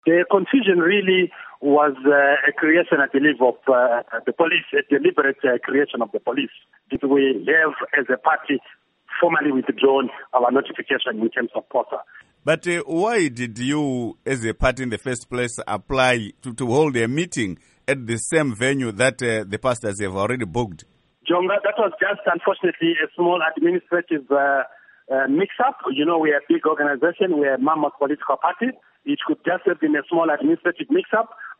Interview With Obert Gutu